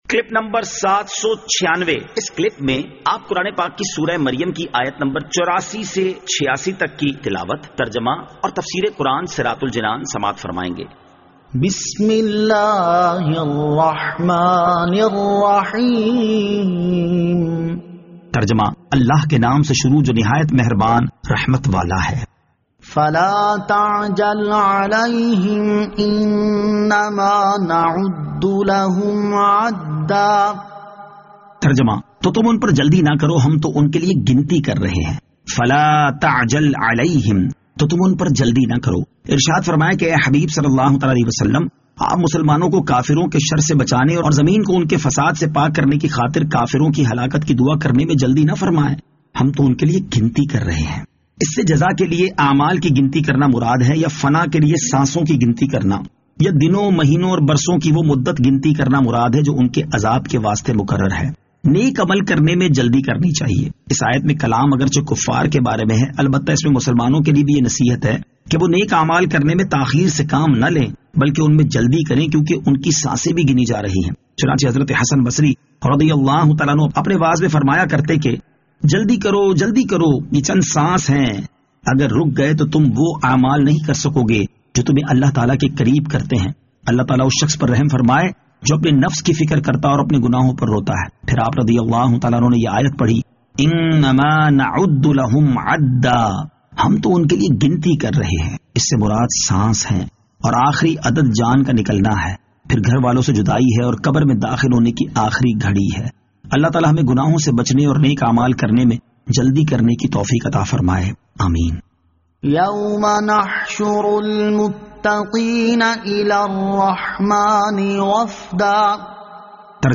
Surah Maryam Ayat 84 To 86 Tilawat , Tarjama , Tafseer